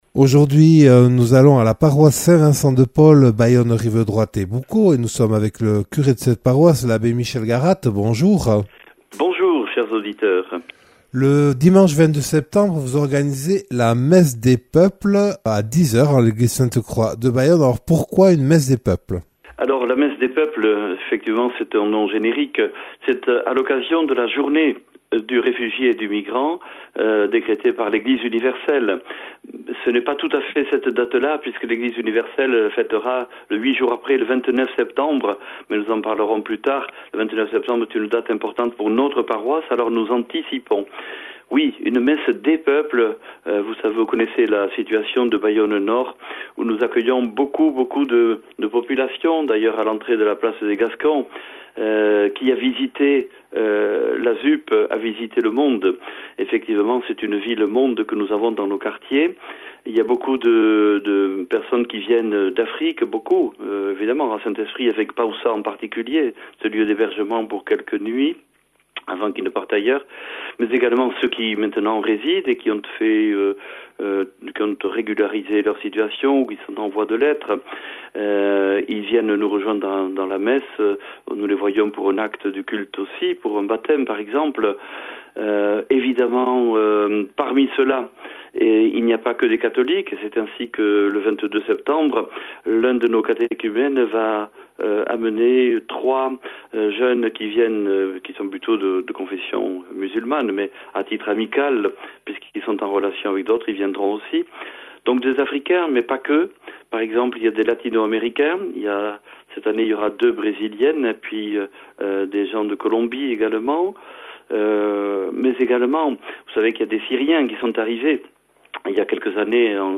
Accueil \ Emissions \ Infos \ Interviews et reportages \ Messe des Peuples le dimanche 22 septembre à Sainte-Croix de (...)